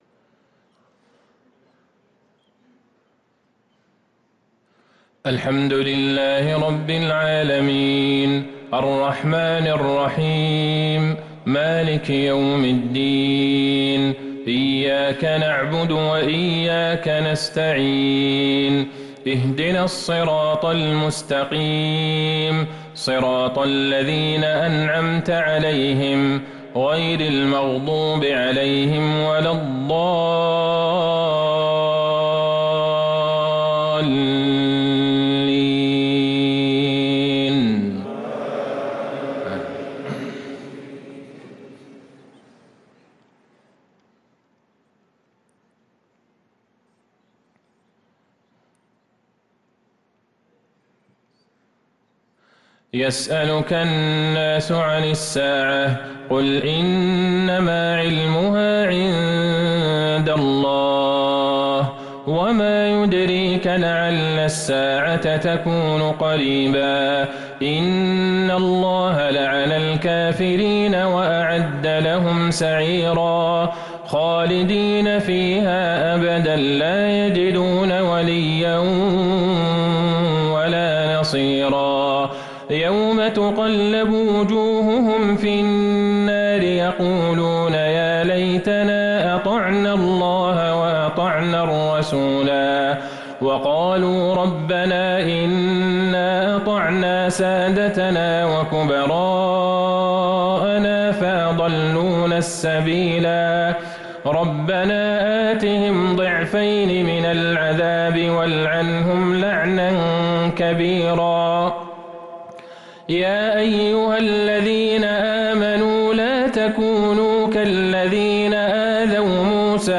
صلاة العشاء للقارئ عبدالله البعيجان 2 جمادي الأول 1445 هـ